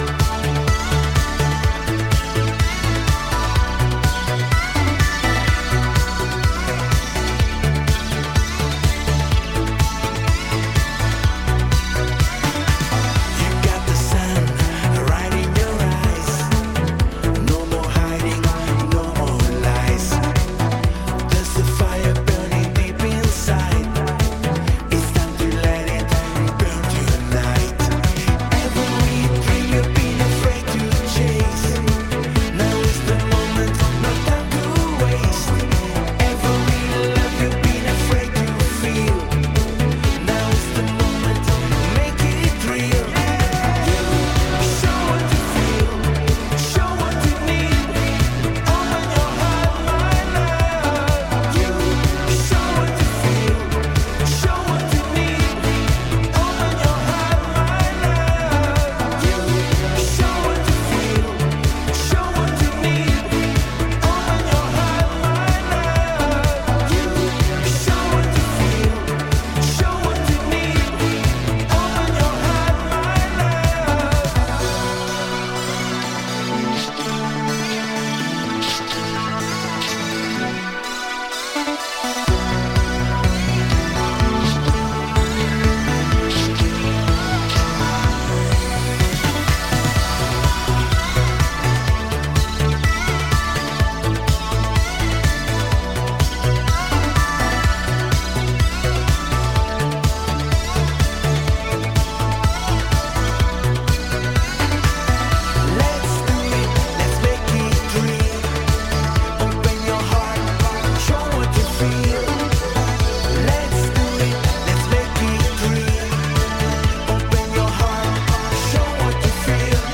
色気たっぷりのヴォーカルをこれまた良い意味でチージーなメロディーで支える